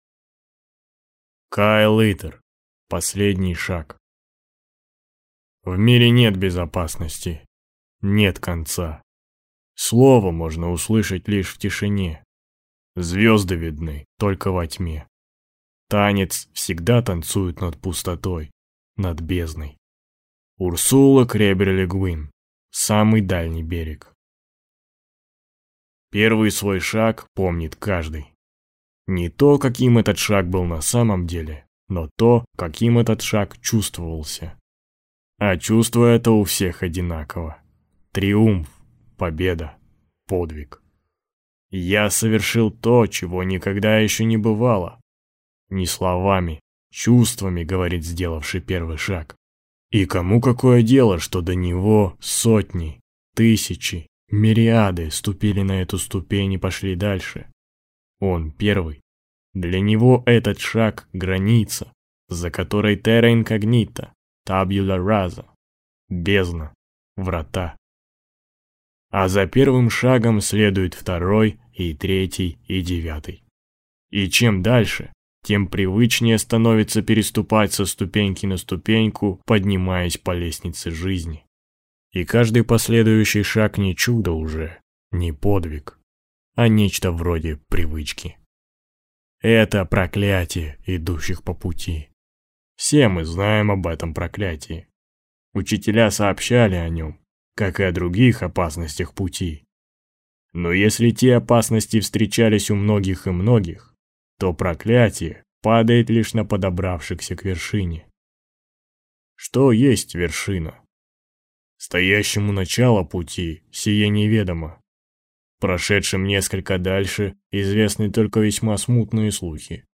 Аудиокнига Последний шаг | Библиотека аудиокниг